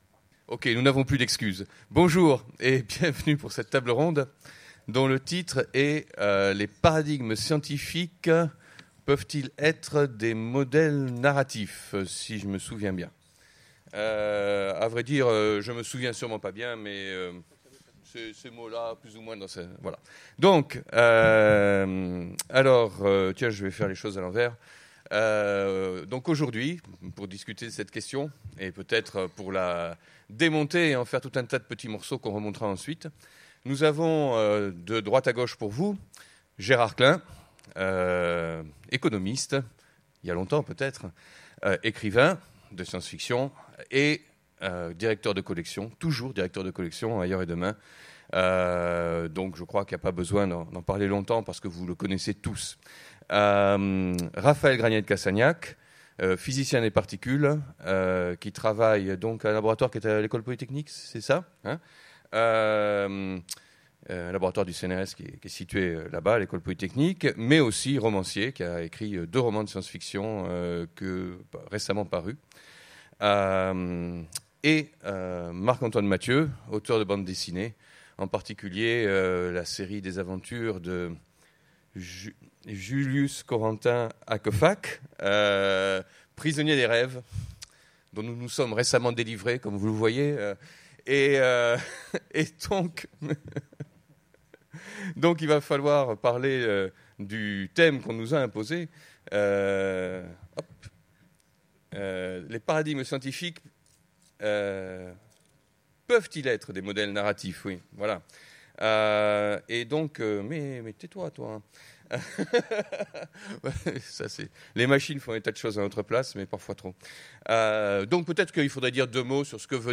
Utopiales 2015 : Conférence Les paradigmes scientifiques peuvent-ils être des modèles narratifs ?